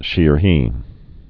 (shēər-hē, shēhē)